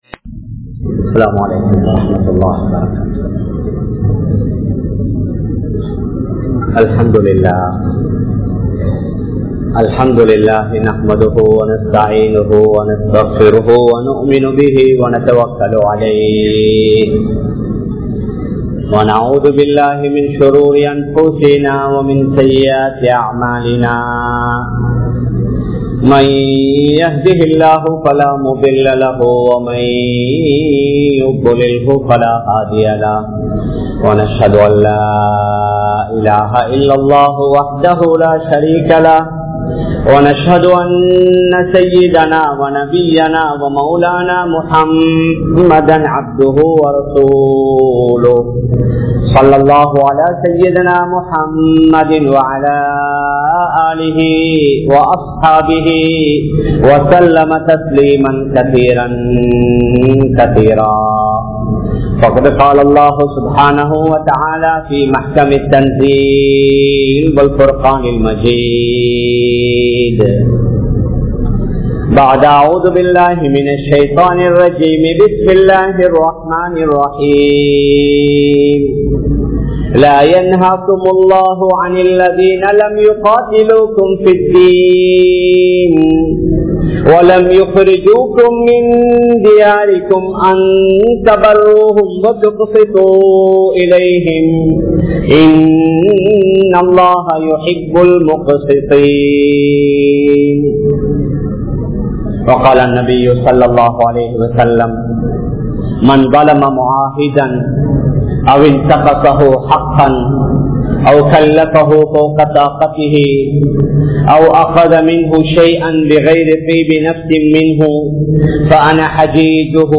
Anniyavarhalun Nabi(SAW)Avarhal Palahiya Murai (அன்னியவர்களுடன் நபி(ஸல்)அவர்கள் பழகிய முறை) | Audio Bayans | All Ceylon Muslim Youth Community | Addalaichenai
Colombo 04, Majma Ul Khairah Jumua Masjith (Nimal Road)